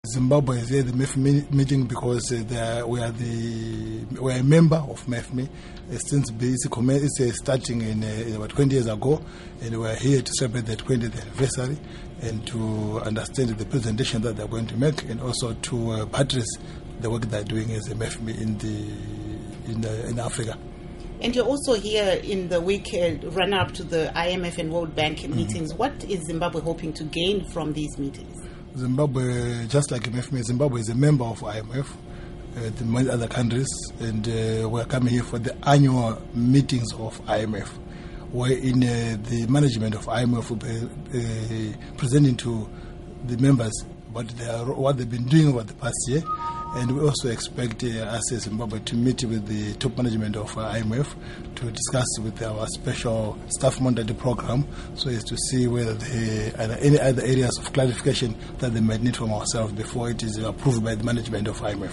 Interview With John Mangudya